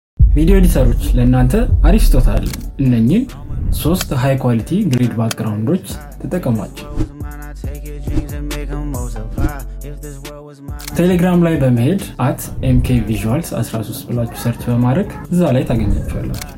Editors use these 3 cinematic sound effects free download